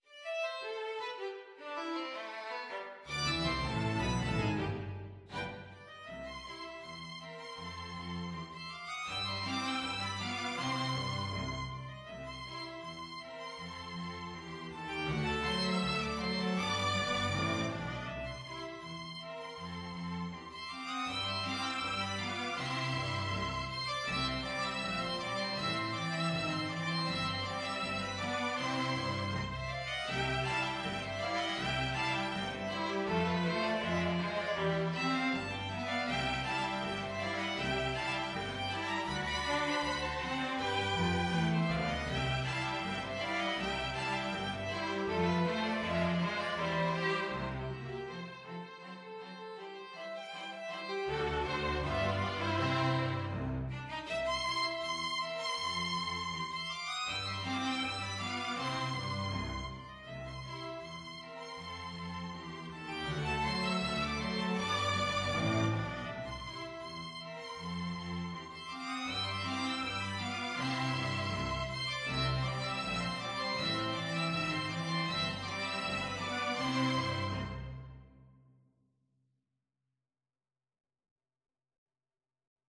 Jazz Scott Joplin The Entertainer String Ensemble version
"The Entertainer" is sub-titled "A rag time two step", which was a form of dance popular until about 1911, and a style which was common among rags written at the time.
G major (Sounding Pitch) (View more G major Music for String Ensemble )
4/4 (View more 4/4 Music)
=250 Presto (View more music marked Presto)
String Ensemble  (View more Intermediate String Ensemble Music)
Jazz (View more Jazz String Ensemble Music)